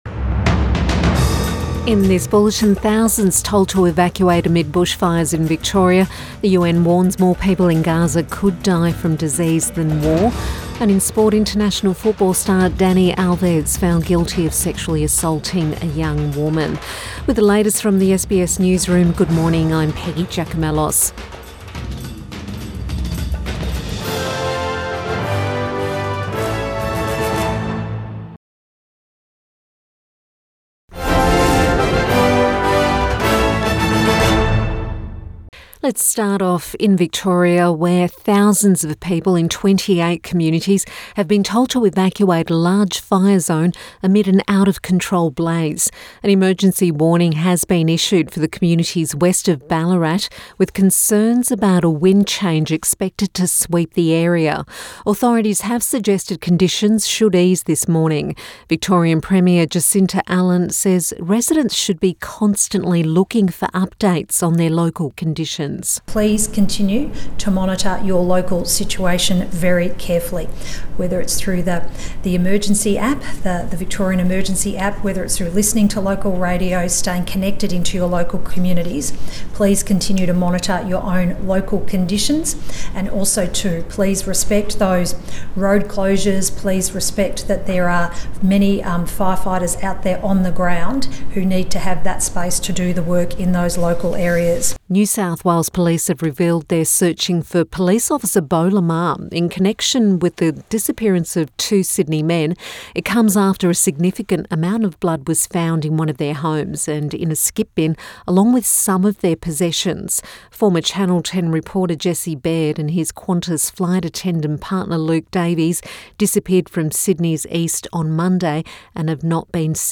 Morning News Bulletin 23 February 2024